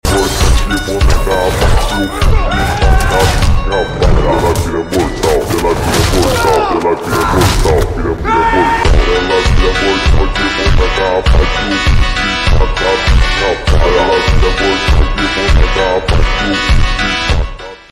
Slowed + Reverb